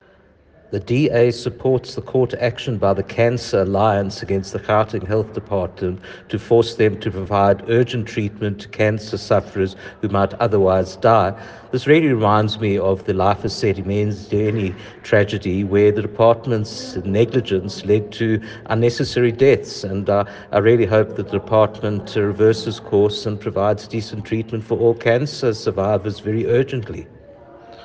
Note to editors: Please find attached an English soundbite by Jack Bloom MPL